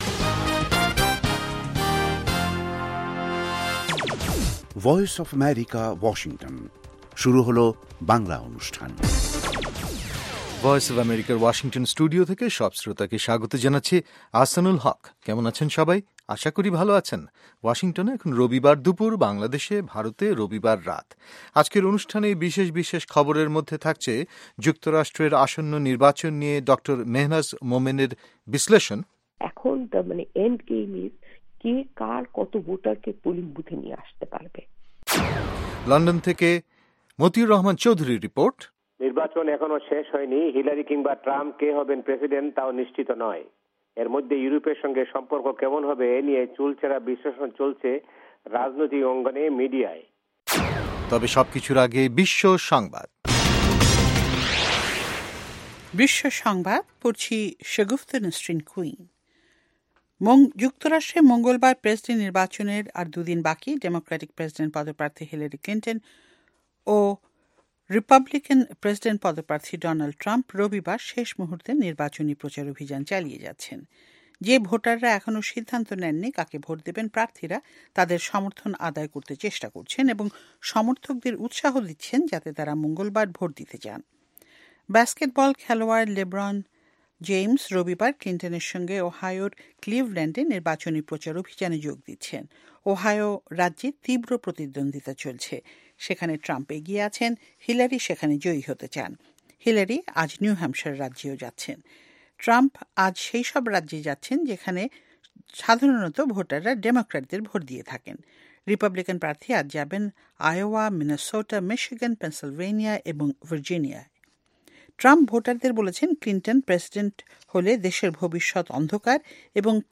অনুষ্ঠানের শুরুতেই রয়েছে আন্তর্জাতিক খবরসহ আমাদের ঢাকা এবং কলকাতা সংবাদদাতাদের রিপোর্ট সম্বলিত বিশ্ব সংবাদ, এর পর রয়েছে ওয়ার্ল্ড উইন্ডোতে আন্তর্জাতিক প্রসংগ, বিজ্ঞান জগত, যুব সংবাদ, শ্রোতাদের চিঠি পত্রের জবাবের অনুষ্ঠান মিতালী এবং আমাদের অনুষ্ঠানের শেষ পর্বে রয়েছে যথারীতি সংক্ষিপ্ত সংস্করণে বিশ্ব সংবাদ।